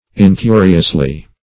incuriously - definition of incuriously - synonyms, pronunciation, spelling from Free Dictionary Search Result for " incuriously" : The Collaborative International Dictionary of English v.0.48: Incuriously \In*cu"ri*ous*ly\, adv.
incuriously.mp3